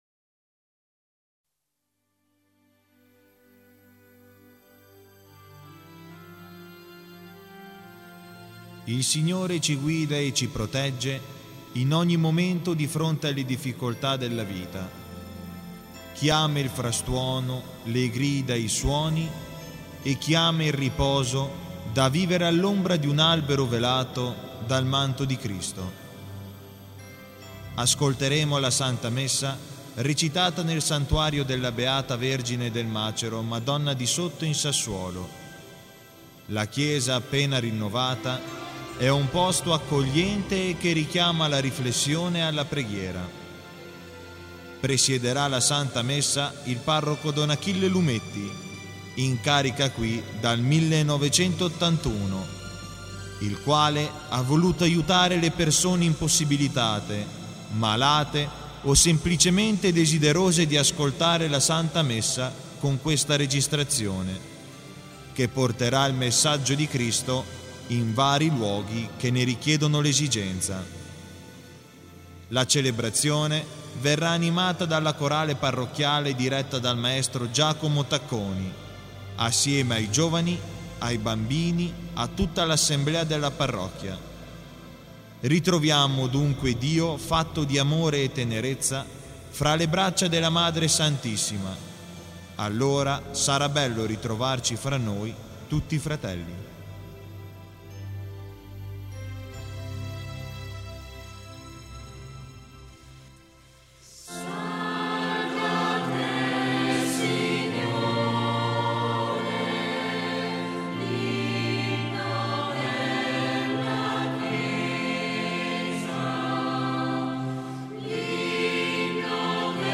santamessa_sassuolo.mp3